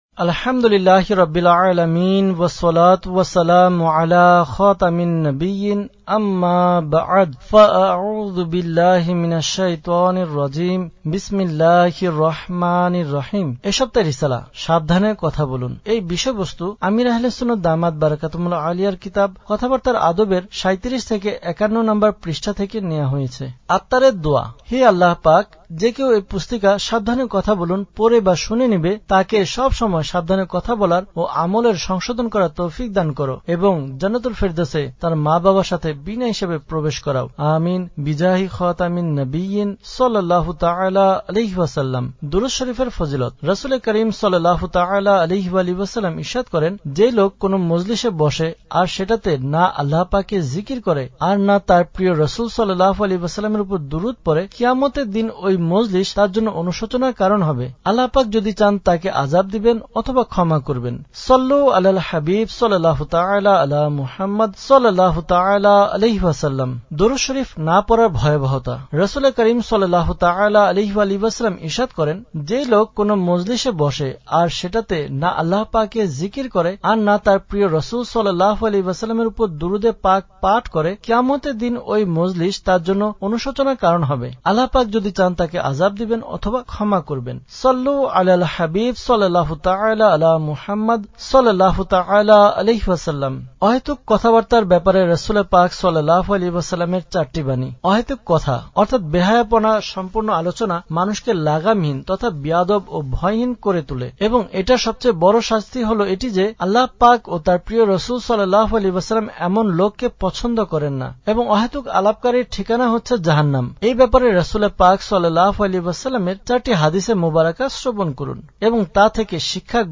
Audiobook - সাবধানে কথা বলুন!